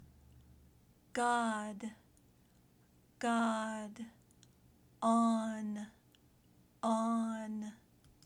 Practice the Short “O” Sound